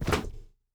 Foley Sports / Soccer / Scuffed Shot C.wav
Scuffed Shot C.wav